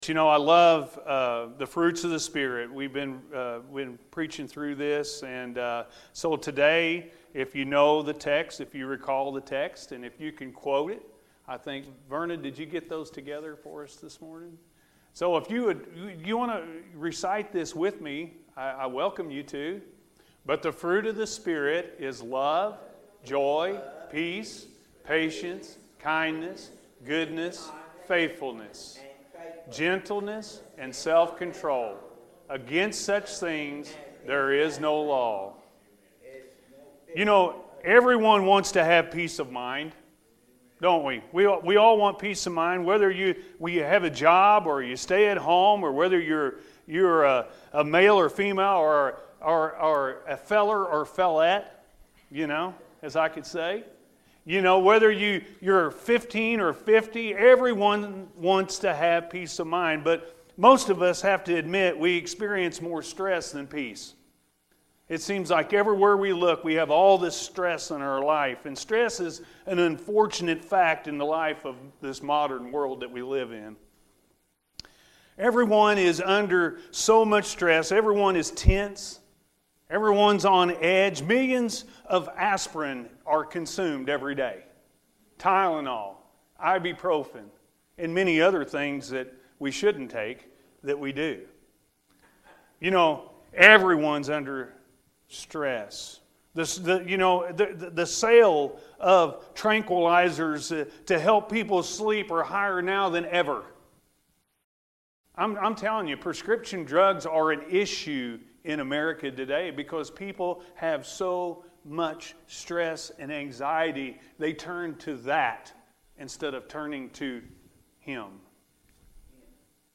Peace-A.M. Service